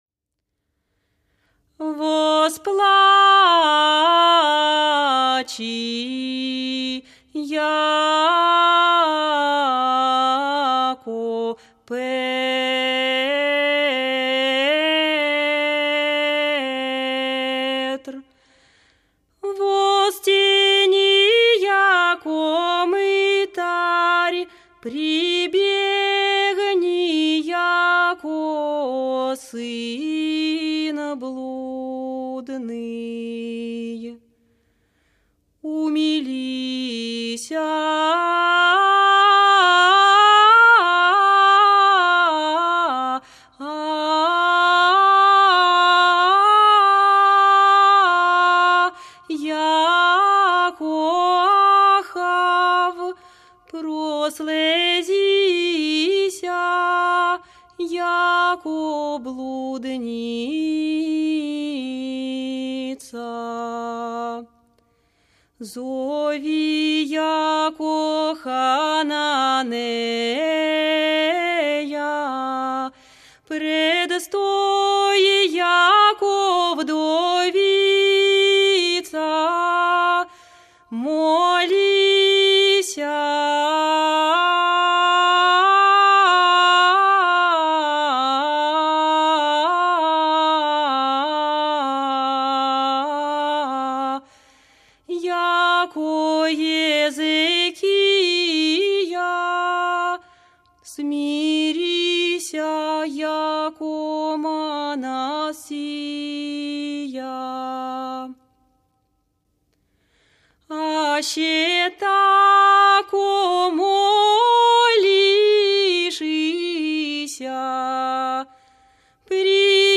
Исполнение стиха «Восплачи яко Петр», знаменный распев,  гл. 5